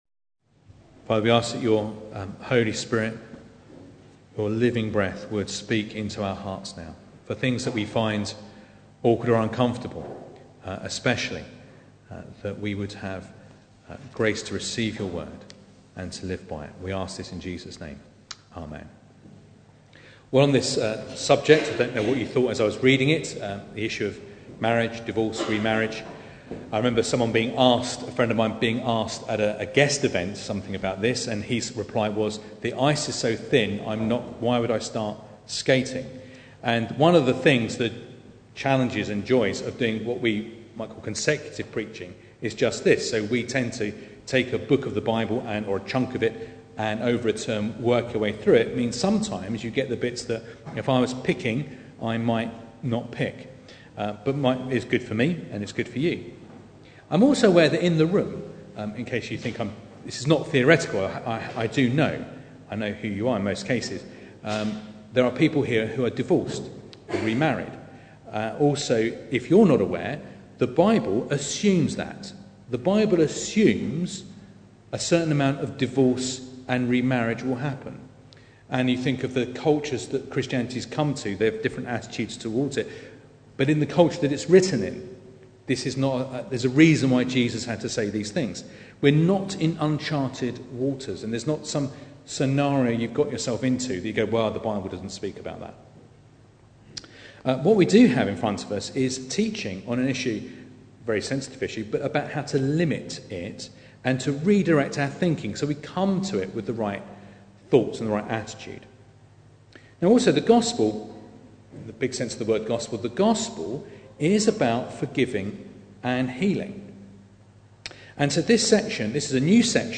Passage: Matthew 19:1-12 Service Type: Sunday Morning